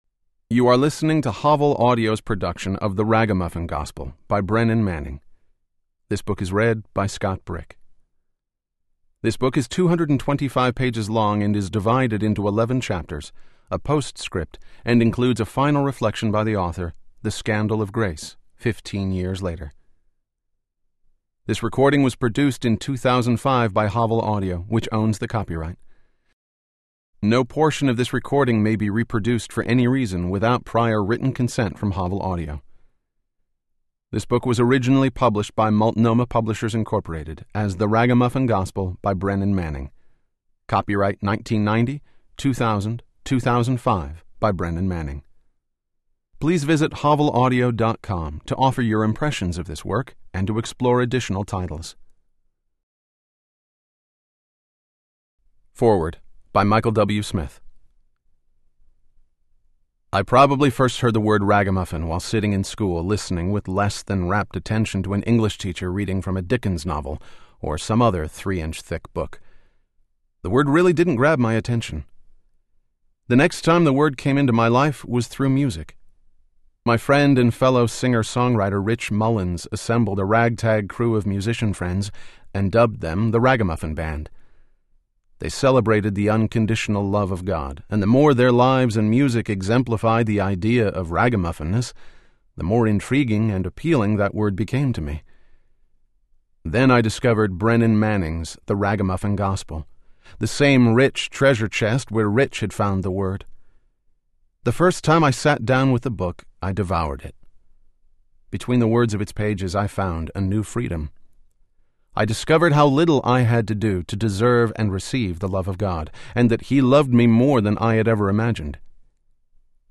The Ragamuffin Gospel Audiobook
Narrator
Scott Brick